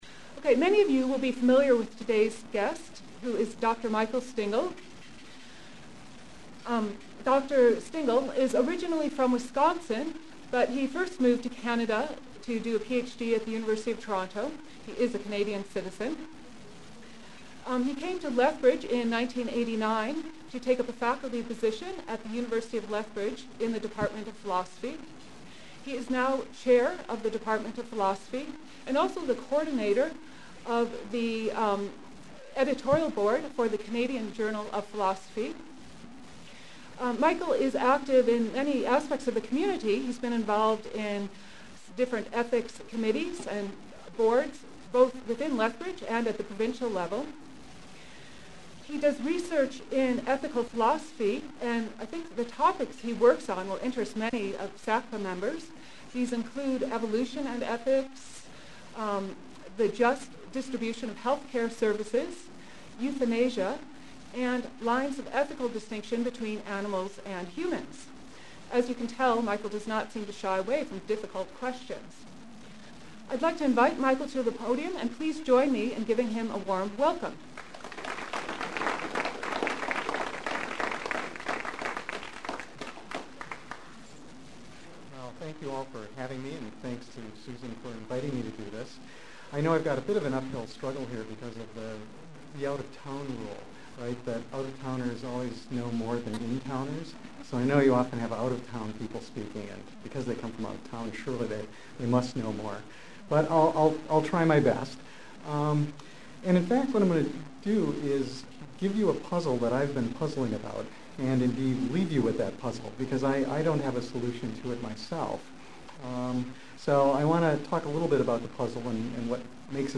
Location: Sven Ericksen’s Family Restaurant (lower level)